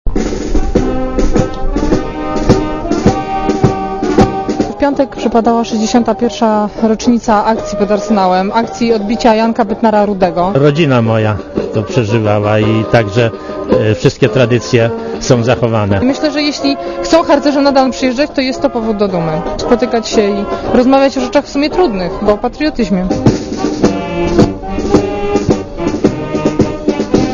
Posłuchaj relacji reporterki Radia ZET (106 KB)